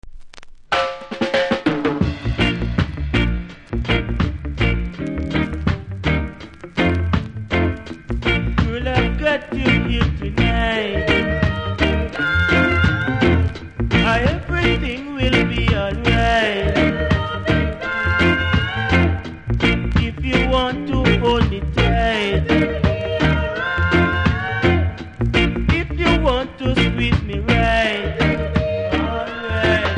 うすキズ多めですが音は良好なので試聴で確認下さい。